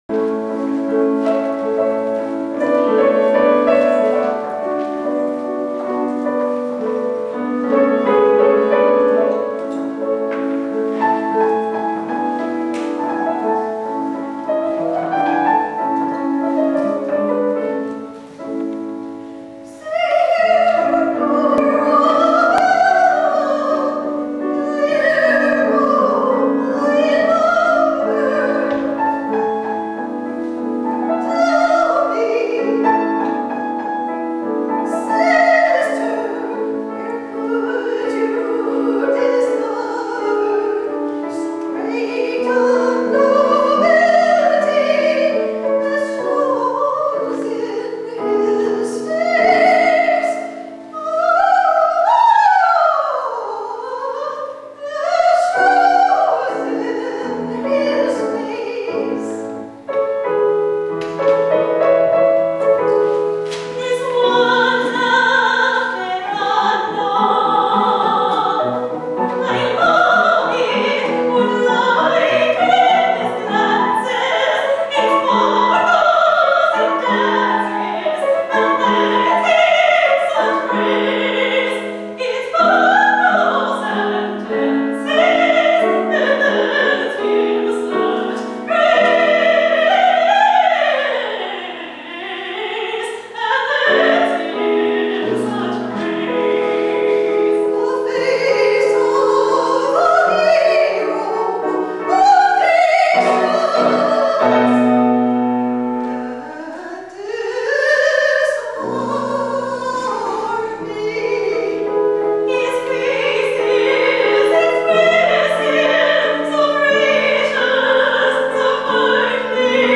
Performing at 2000 concert
In 2000, I peformed a concert in Palo Alto, CA. Below are audios of what I sang by clicking on a title listed below.
I performed duets with both of them.